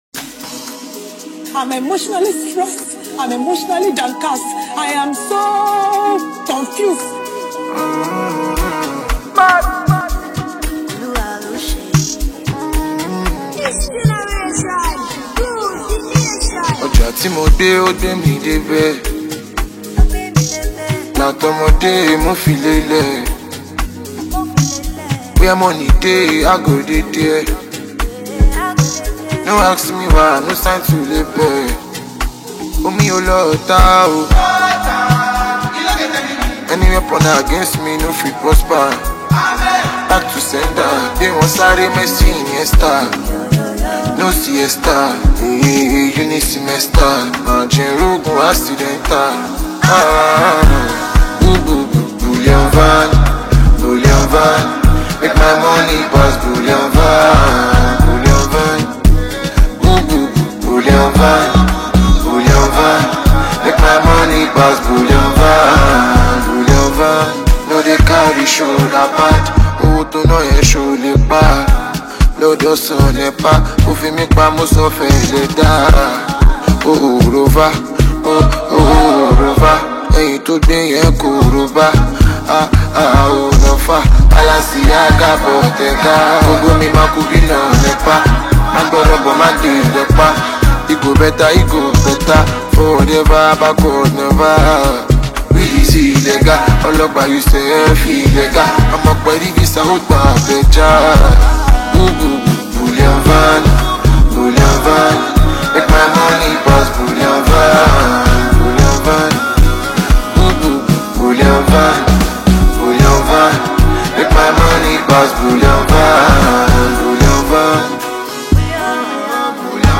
Fast rising street hop singer and songwriter